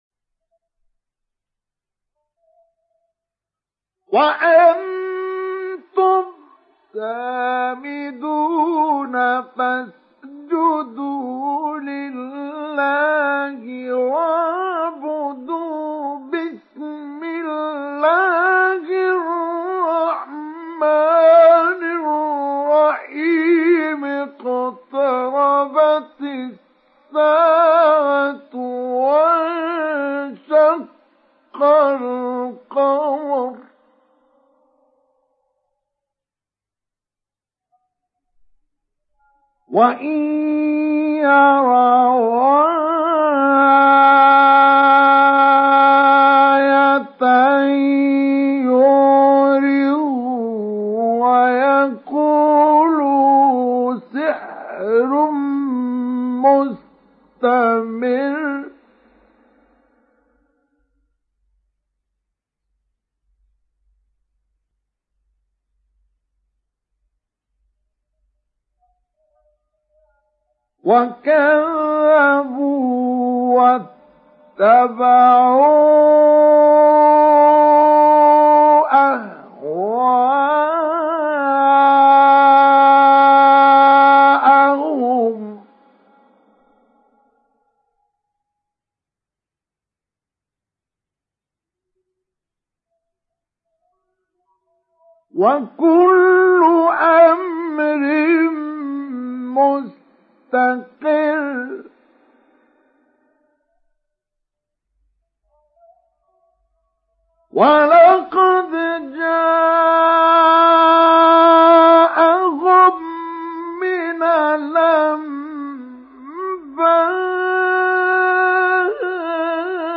Télécharger Sourate Al Qamar Mustafa Ismail Mujawwad